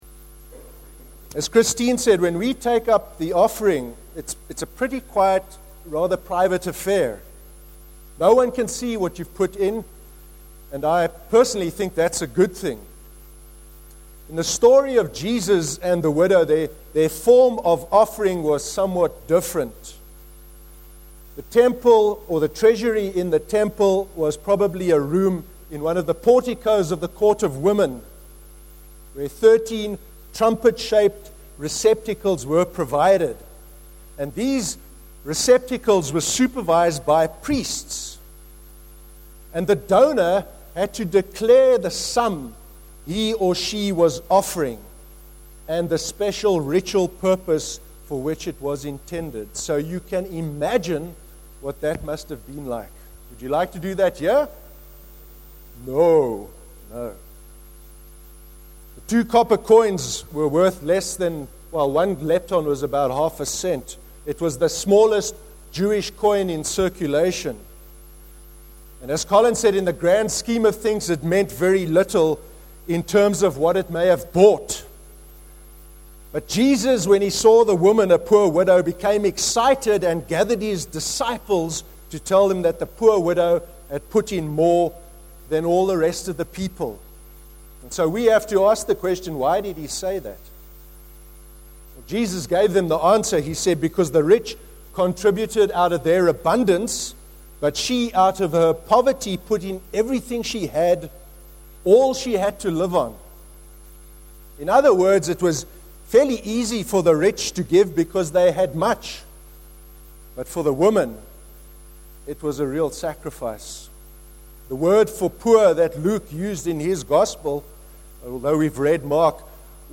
On Sunday 3rd November 2013, as part of our monthly All Age service, we considered the Story of the Widow’s Offering from Mark 12:41-44, and integrity and relationship with God.
A recording of the service’s sermon is available to play below, or by right clicking on this link to download the sermon to your computer.